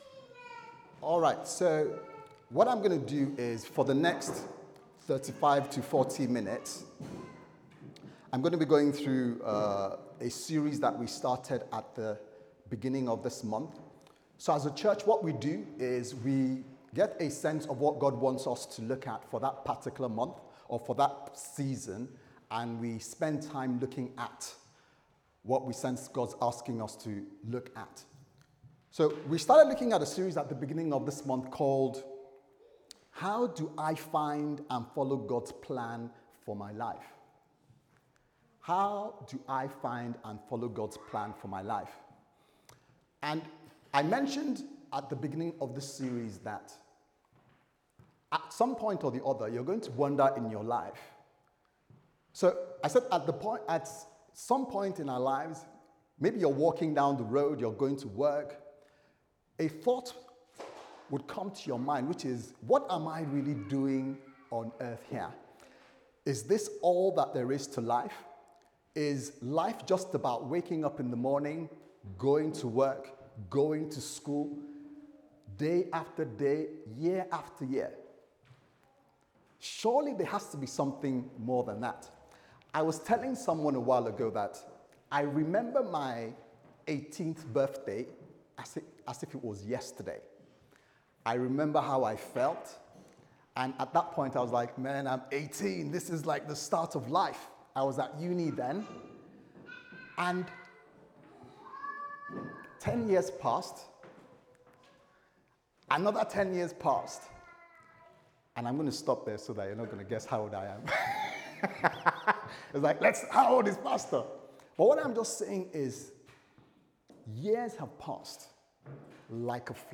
How Can I Find God's Will For My Life Service Type: Sunday Service Sermon « How Do I Find And Follow God’s Plans For My Life